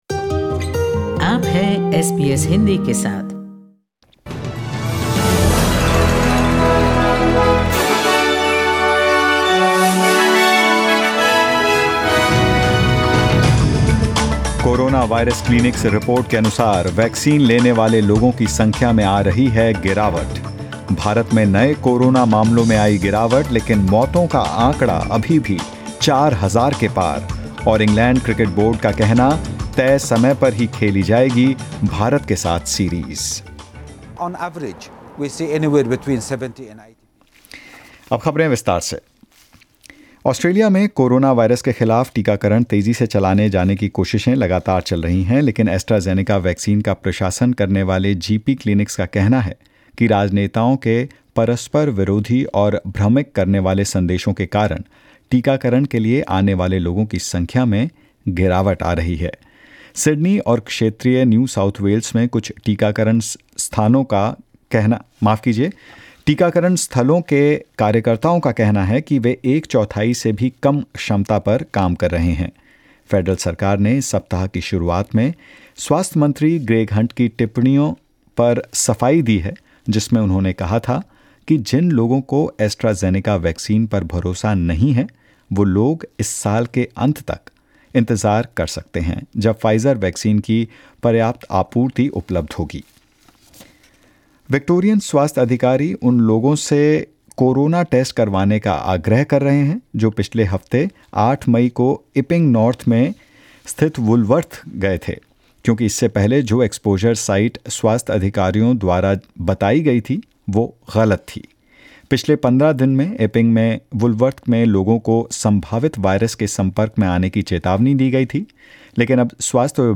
In this latest SBS Hindi News bulletin of India and Australia: India reports record deaths as new cases dip; ECB and BCCI say 'no official request' made for change of schedule of Test series and more.